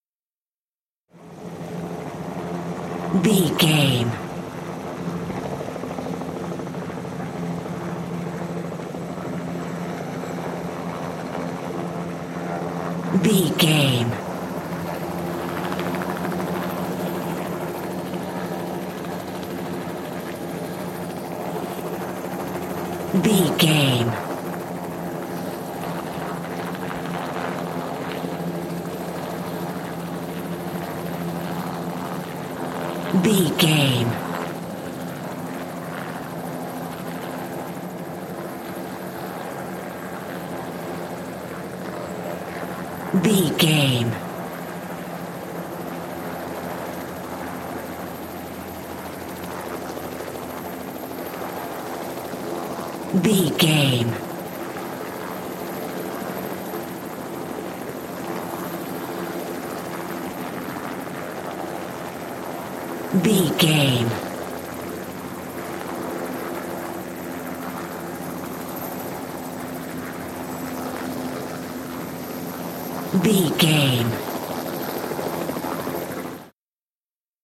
Helicopter fly arround ext 366
Sound Effects